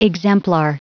Prononciation du mot exemplar en anglais (fichier audio)